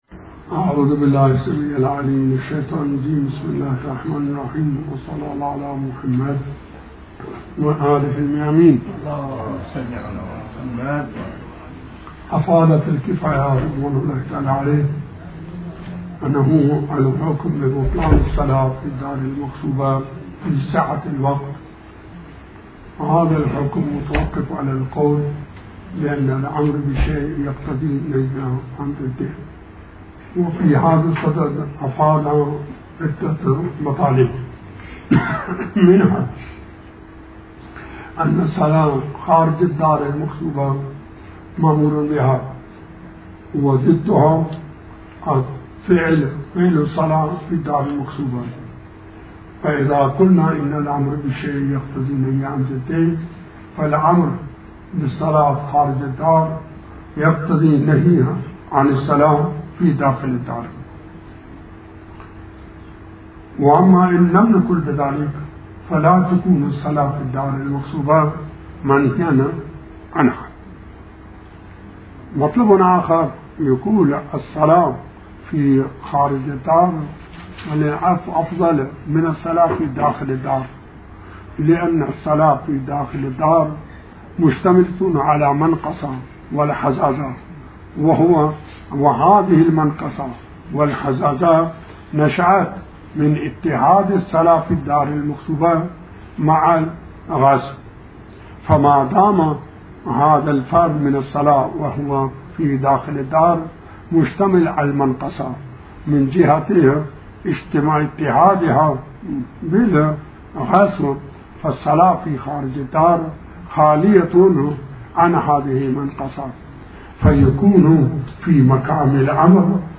تحمیل آیةالله الشيخ بشير النجفي بحث الفقه 38/03/19 بسم الله الرحمن الرحيم الموضوع : التيمم _ شرائط التيمم _ (مسألة 9) : إذا كان على الباطن نجاسة لها جرم يعد حائلا ولم يمكن إزالتها فالأحوط الجمع بين الضرب به والمسح به، والضرب بالظاهر والمسح به .